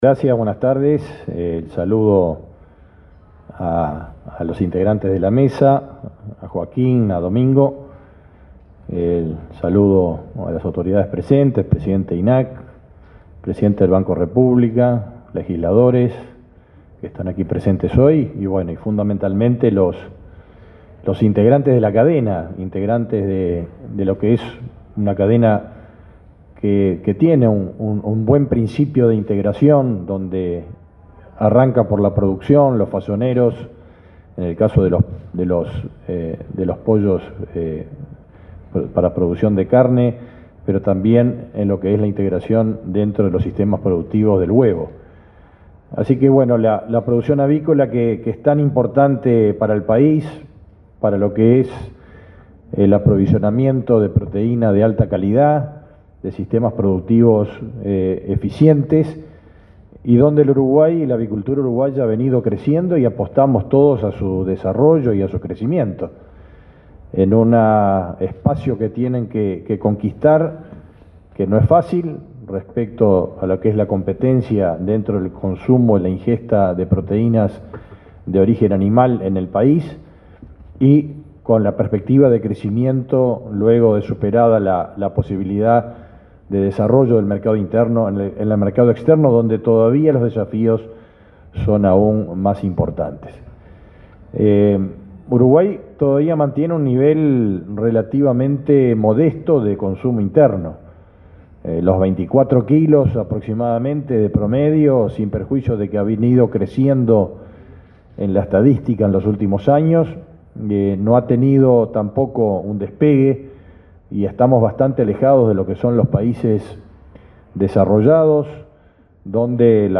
Palabras del ministro de Ganadería, Fernando Mattos
Palabras del ministro de Ganadería, Fernando Mattos 25/09/2024 Compartir Facebook X Copiar enlace WhatsApp LinkedIn El ministro de Ganadería, Fernando Mattos, participó, el martes 24 en la Torre Ejecutiva, en el segundo foro internacional sobre la exportación de carne aviar.